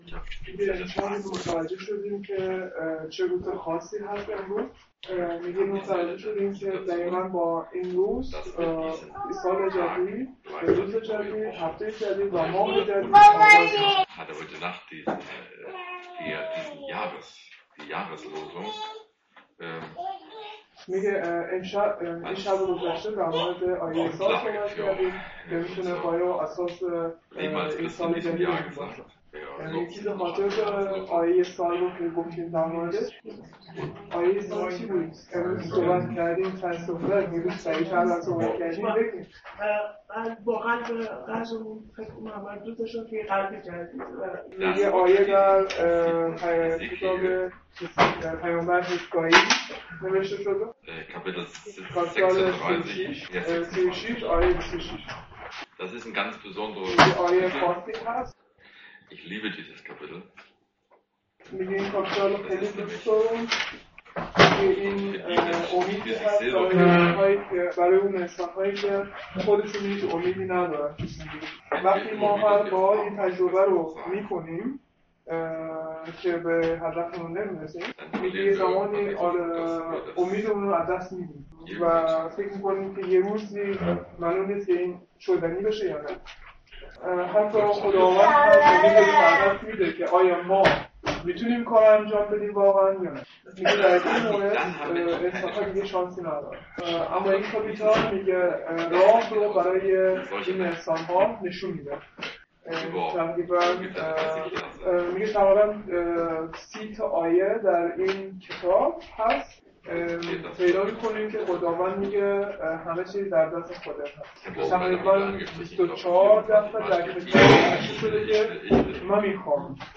حزقیال 26,36 | Predigt vom 01.01.2017 zu Hesikel Kapitel 36 Vers 26 | Übersetzung in Farsi